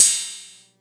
VEC3 Ride
VEC3 Cymbals Ride 04.wav